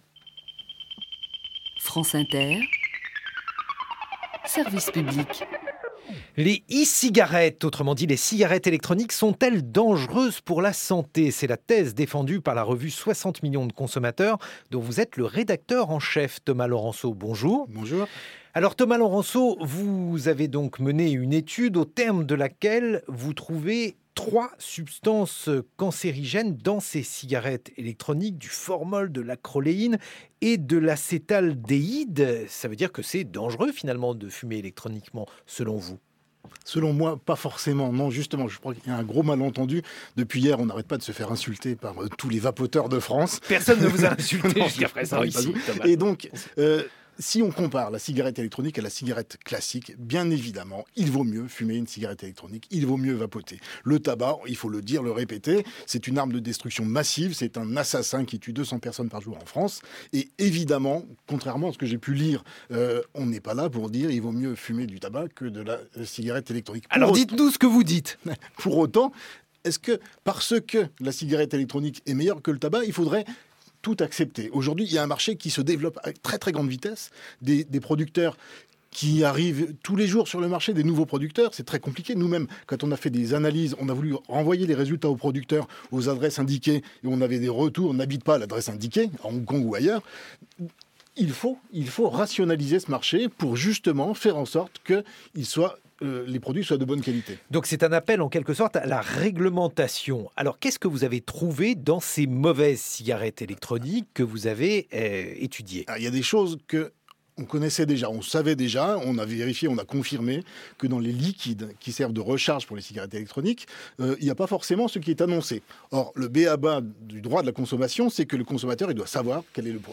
Et uniquement l'interview en ogg